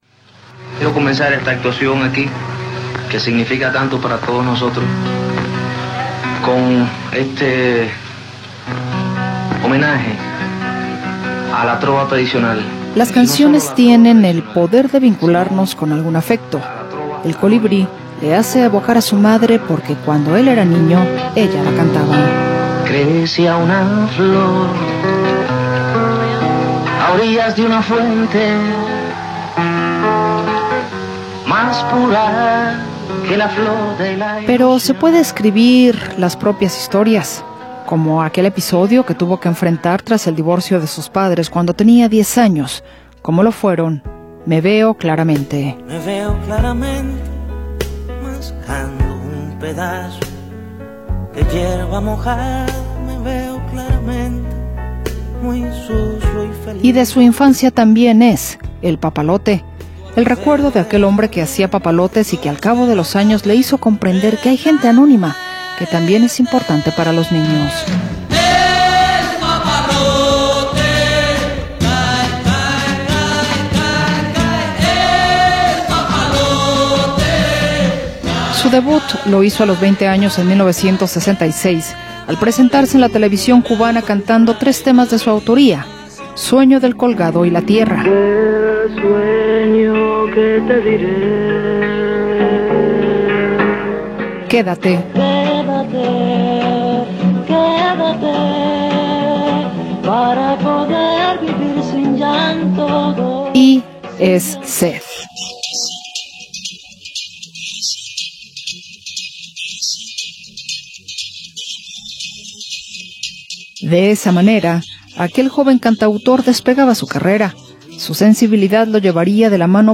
En “El Sonido de la Música”, el cantautor Silvio Rodríguez, nos comparte su sensibilidad que ha transitado por las letras del amor y las vivencias propias, para desembocar en el infinito mar de la conciencia y la empatía ante las injusticias que se han denunciado a través de su canto. Artista icónico de la Nueva Trova, el cubano festeja hoy 76 años.